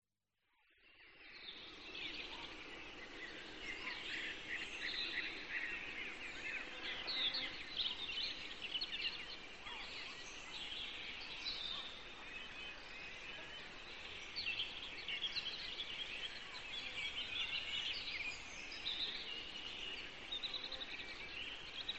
Звуки природы
Шепот тихого шума леса